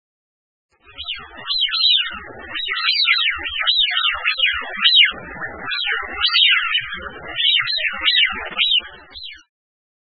2126b「鳥の鳴声」
〔ヒドリガモ〕ピューイー（雄）／湖沼や港湾で越冬，普通・冬鳥，49p，雌雄異色
hidorigamo.mp3